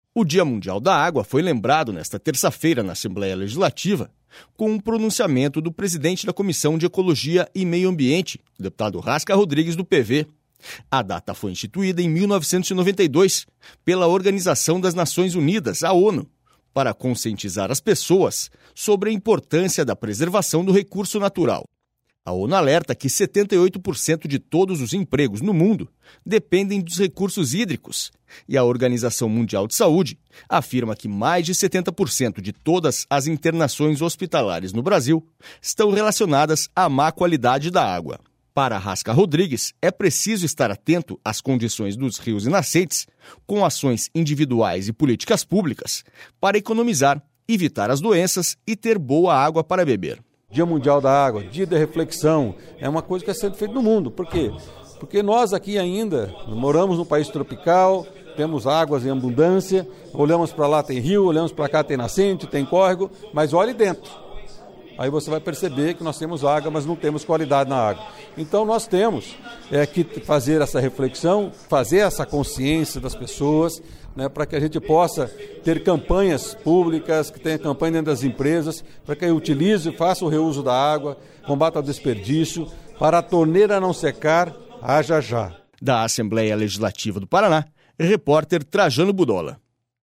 Rasca Rodrigues homenageia em plenário o Dia Mundial da Água